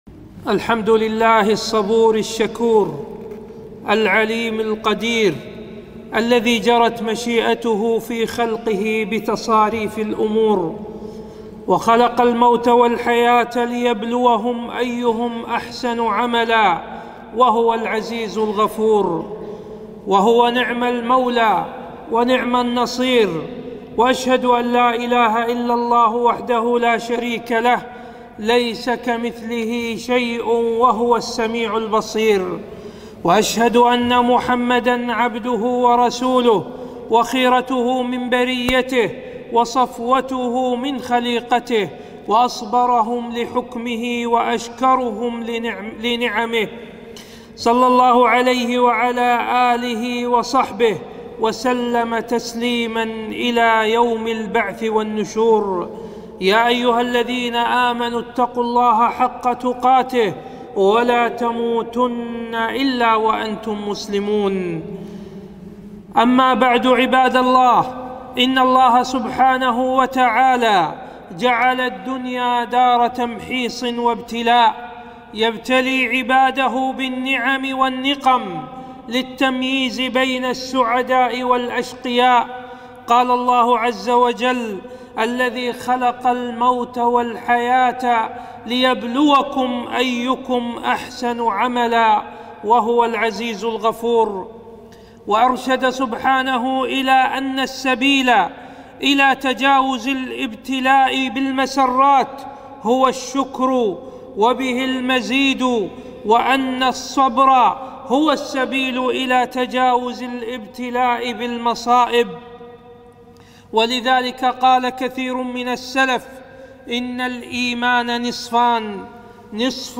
خطبة - التحذير من الانتحار